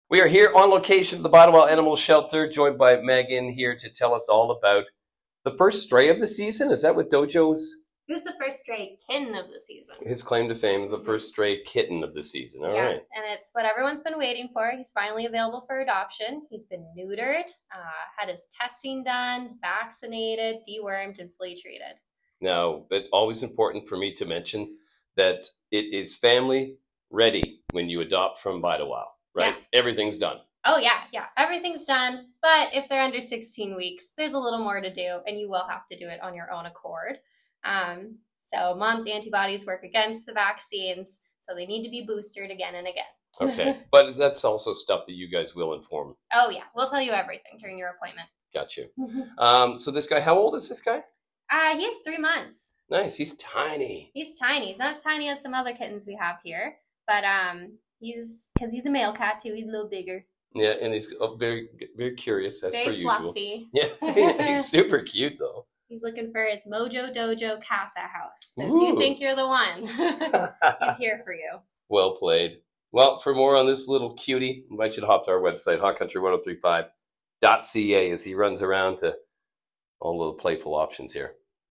Have a listen to our chat with the Bide Awhile crew…